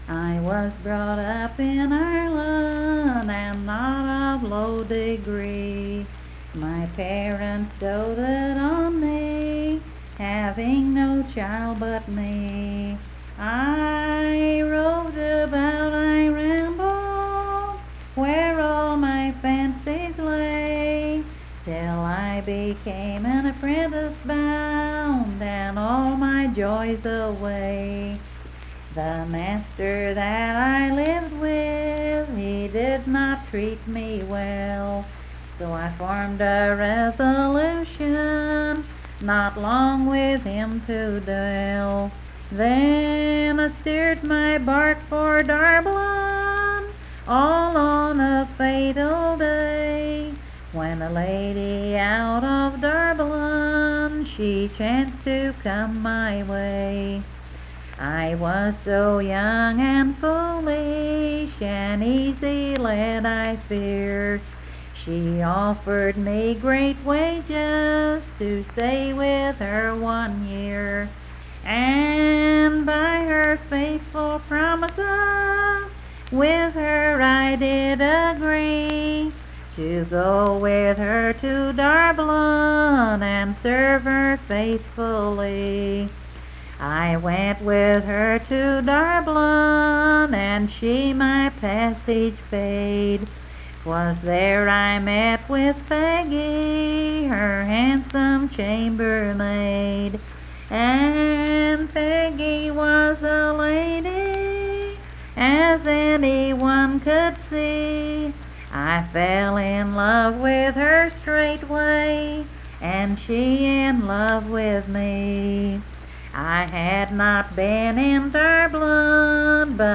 Unaccompanied vocal music
Verse-refrain 8d(4).
Performed in Coalfax, Marion County, WV.
Voice (sung)